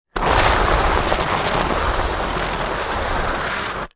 Звуки огнетушителя
На этой странице собраны звуки огнетушителя: шипение пены, нажатие рычага, распыление состава.
Шум пенного огнетушителя в работе